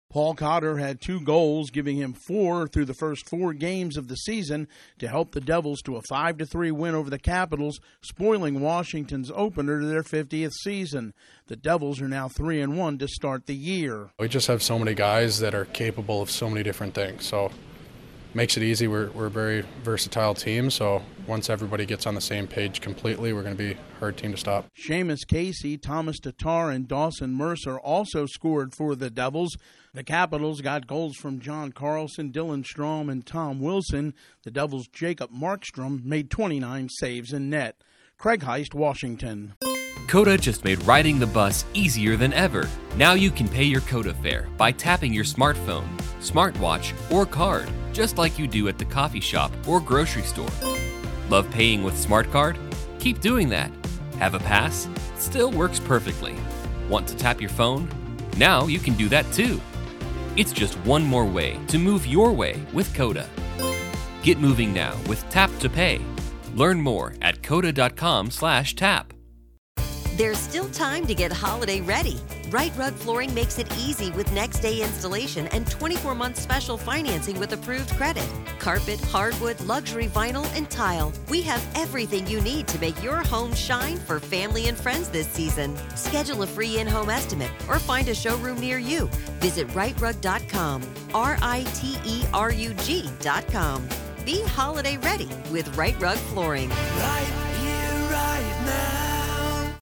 The Devils continue their impressive start to the season. Correspondent